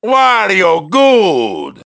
One of Wario's voice clips in Mario Kart 7